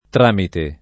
tr#mite] s. m. («passaggio») — es. con acc. scr.: Nel tràmite mio fuggitivo [